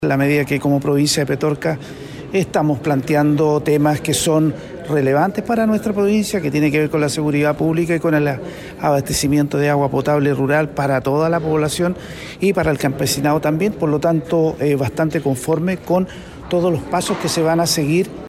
El delegado presidencial provincial de Petorca, Luis Soto, valoró la decisión sostenida, señalando que se encuentran en buen puerto para la realización de estas medidas.
cu-monsalve-en-petorca-delegado-soto.mp3